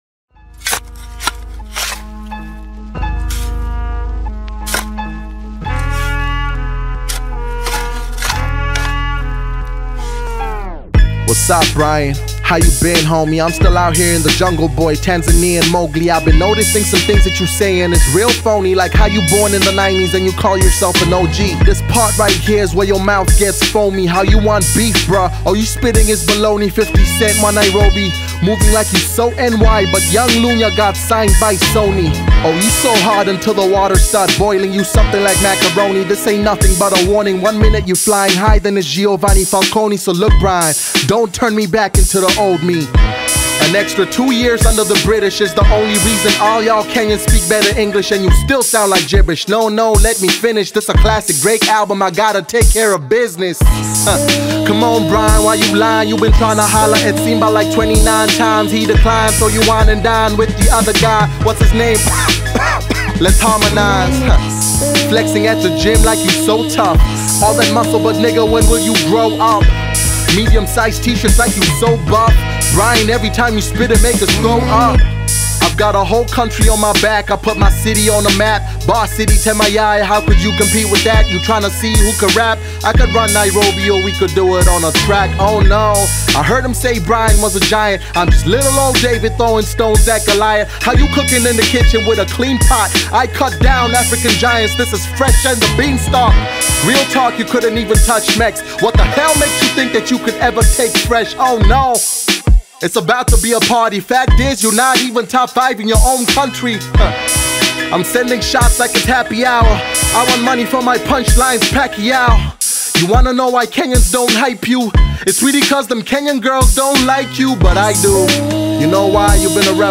Mp3 Download Tanzanian Bongo Flava artist
diss track
African Music